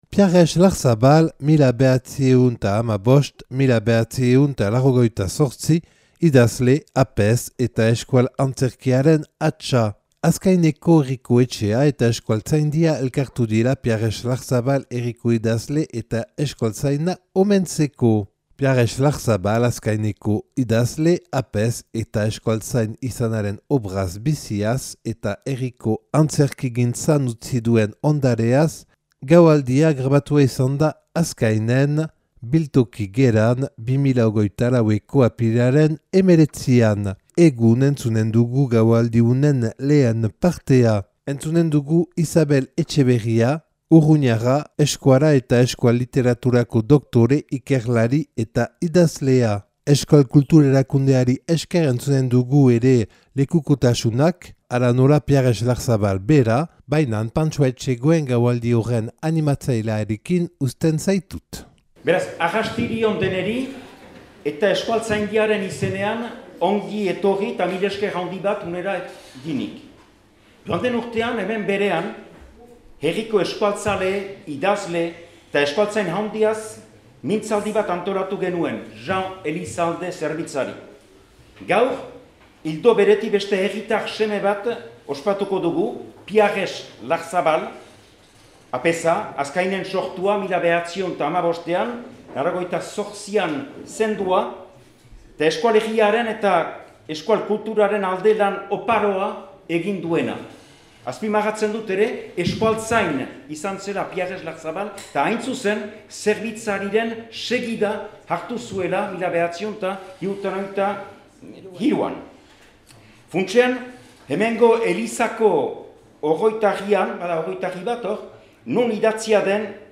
(Azkainen grabatua 2024. apirilaren 19an. Euskaltzaindia, Euskal Kultur Erakundea eta Azkainko herriak antolaturik).